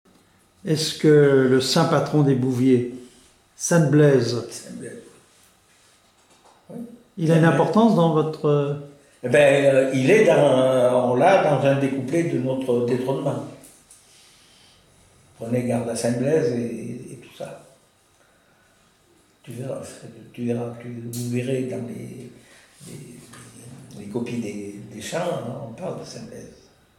Enquête Enquête ethnologique sur les fêtes des bouviers et des laboureurs avec l'aide de Témonia
Catégorie Témoignage